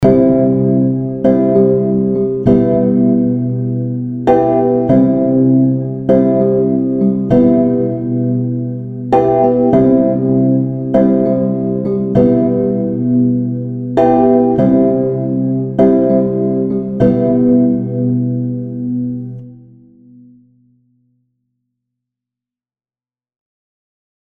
Das DUO Organic Chimer bietet einen wunderbar warmen, umhüllenden Retro-Klang, dieses Mal ohne Vinylgeräusche:
Eine echte Alternative zu Streichern.